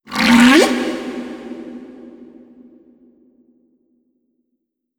khloCritter_Male02-Verb.wav